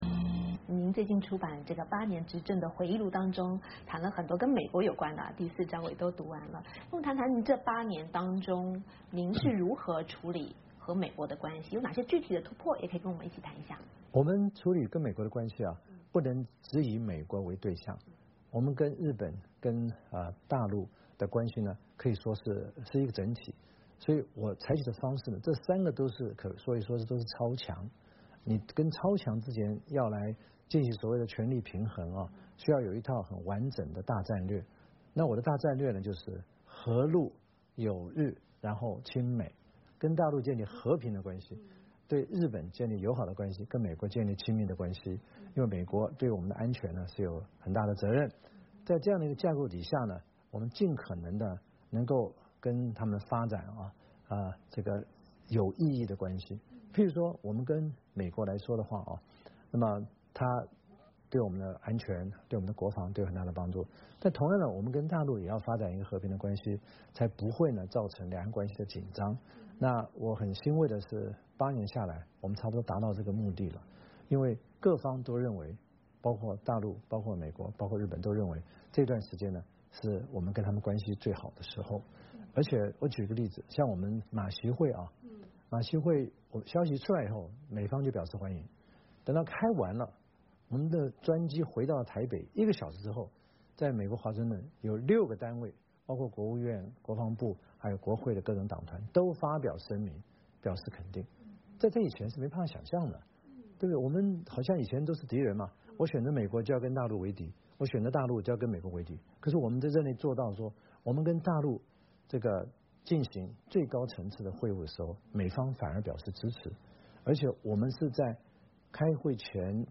VOA专访：台湾前总统马英九回顾执政八年对美外交